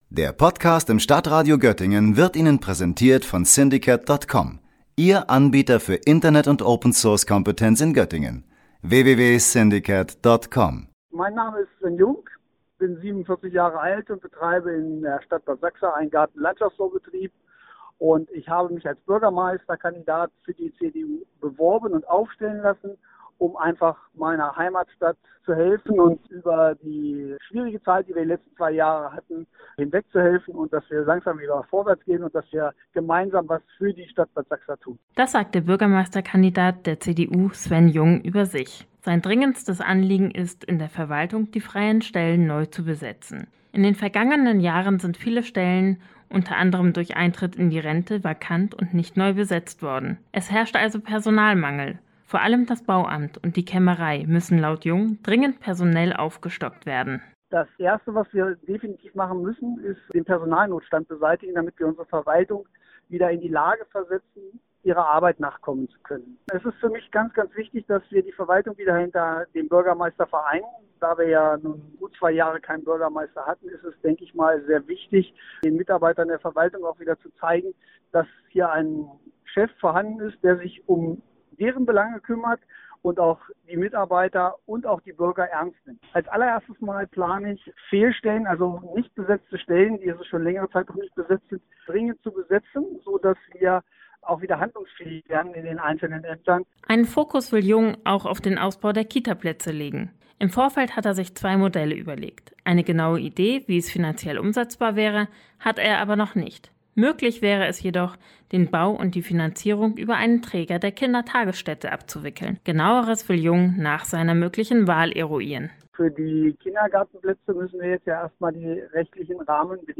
Kandidatenporträt